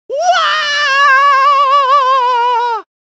Mario Scream